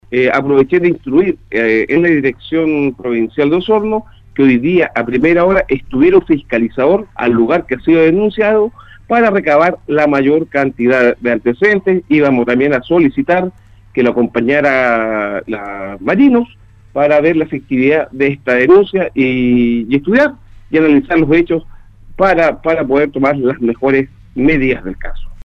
En conversación con Radio Sago el seremi de Bienes Nacionales de Los Lagos, Jorge Moreno se refirió a la denuncia realizada por vecinos que ya se ha viralizado a través de redes sociales contra un particular acusado de cortar el único camino que da conectividad a cerca de 40 familias residentes en la playa grande de Las Gaviotas.